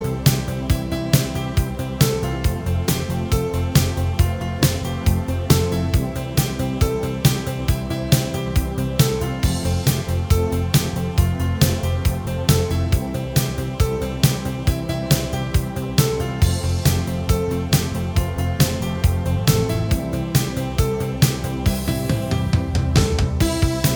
Minus All Guitars Pop (1980s) 3:27 Buy £1.50